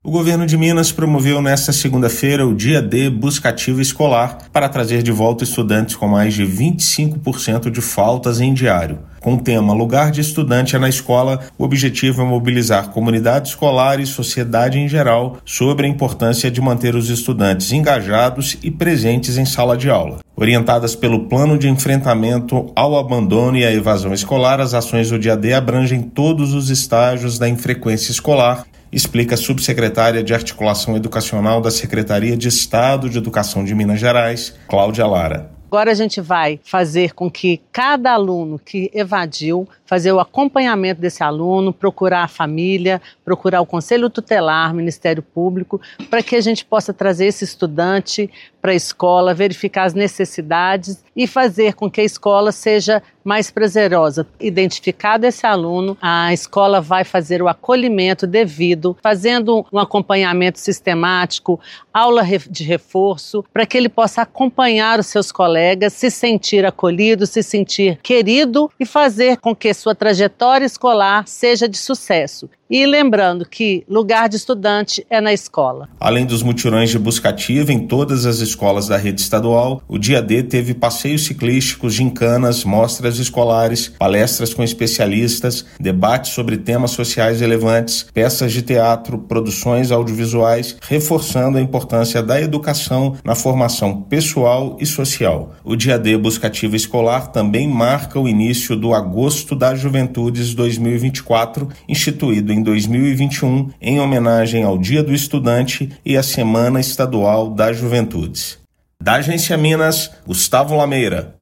Dia D Busca Ativa Escolar marca o início do Agosto das Juventudes 2024 nas instituições da rede estadual. Ouça matéria de rádio.